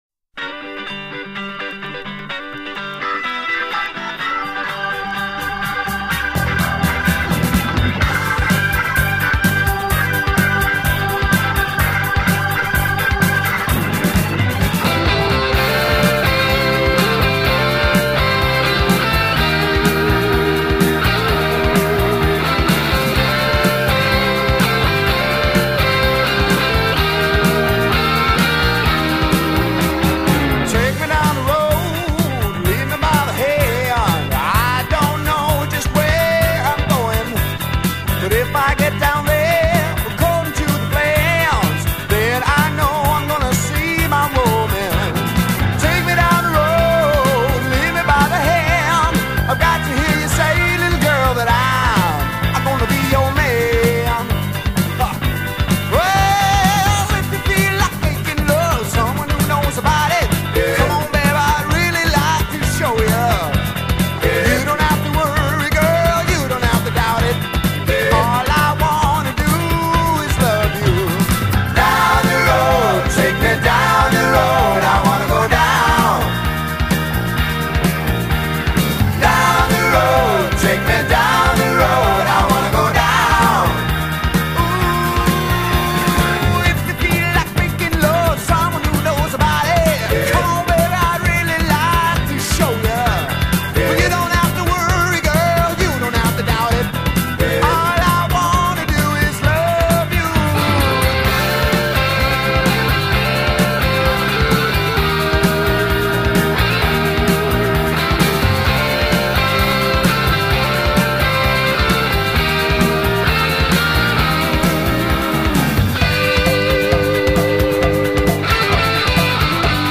Audio/studio version (temporary link):